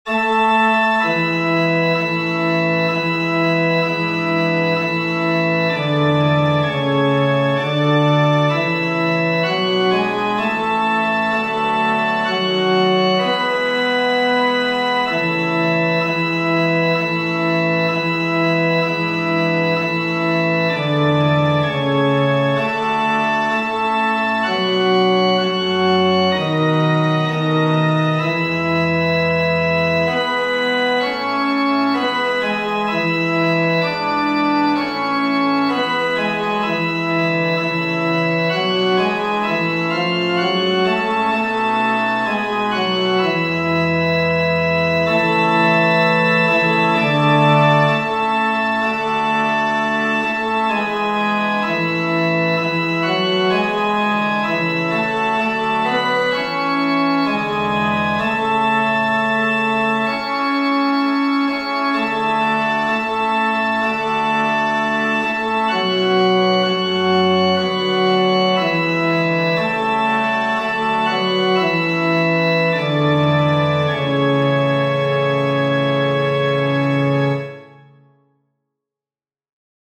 cisar_hymna-baryton.mp3